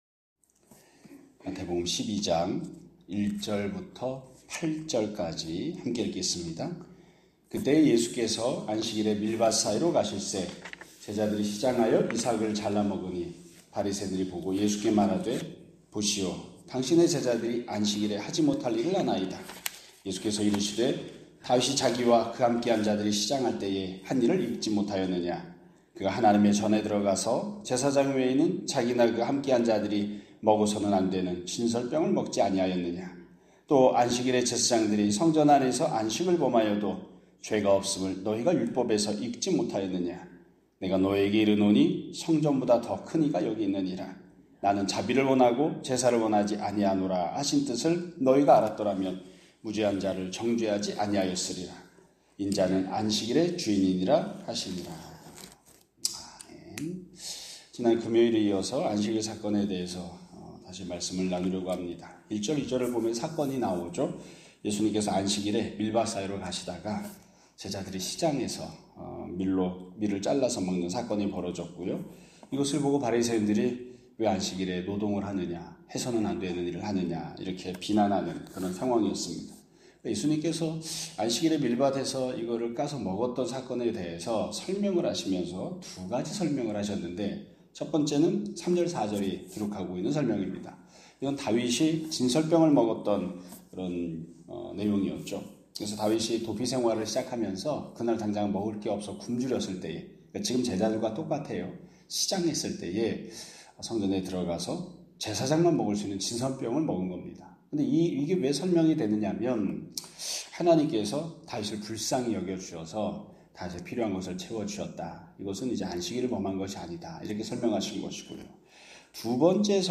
2025년 9월 8일 (월요일) <아침예배> 설교입니다.